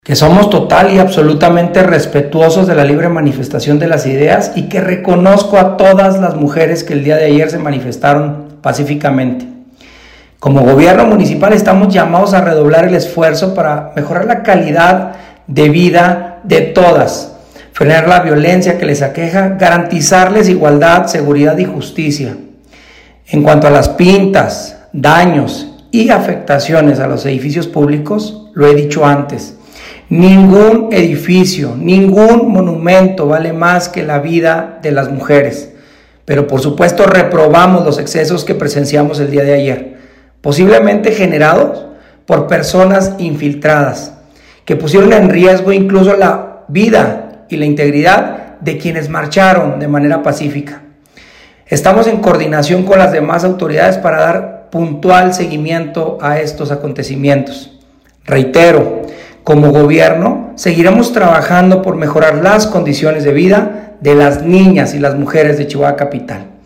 En relación a las manifestaciones del día de ayer, 8 de Marzo, el alcalde de Chihuahua Capital, emitió un mensaje, el cual se reproduce de manera íntegra, a continuación: